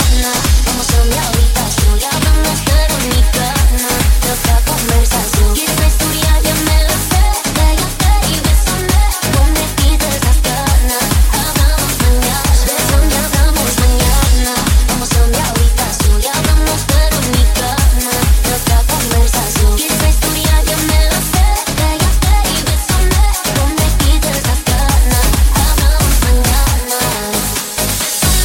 Genere: tribal,anthem,circuit,remix,hit